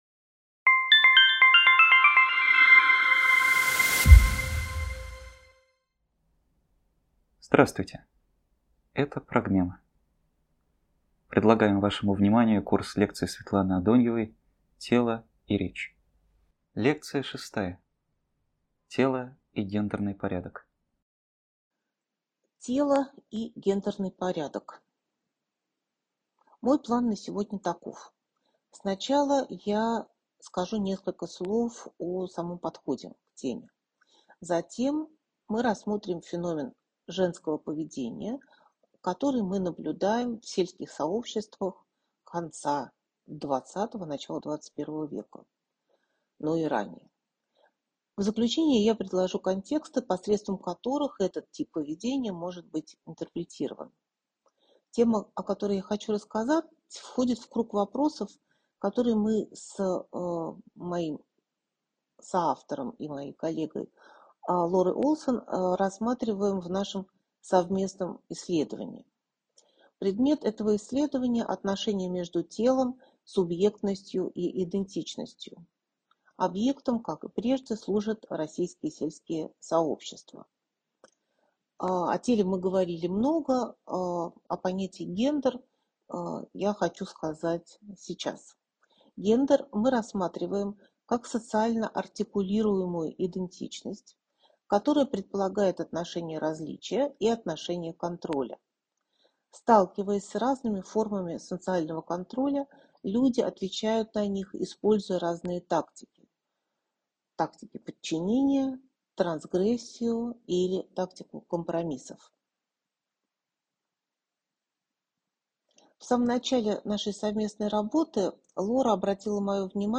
Тело и речь. Лекция 6.